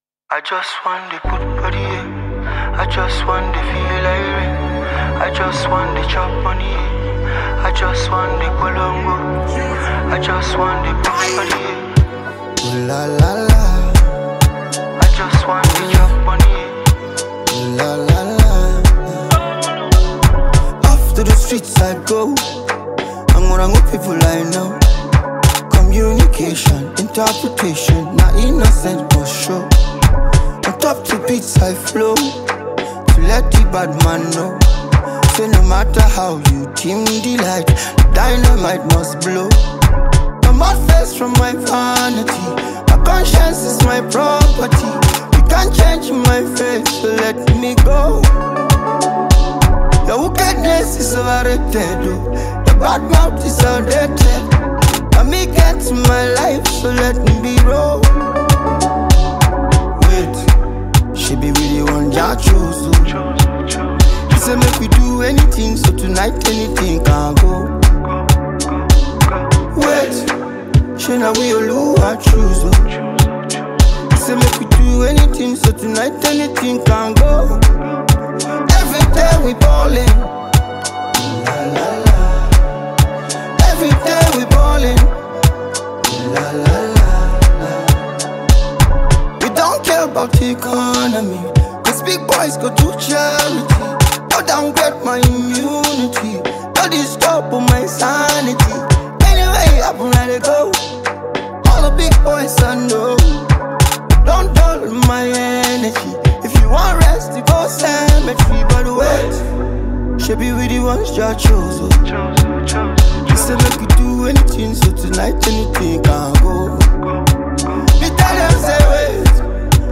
a smooth and uplifting record that celebrates growth
With his signature calm delivery and timeless sound